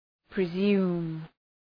Προφορά
{prı’zu:m}
presume.mp3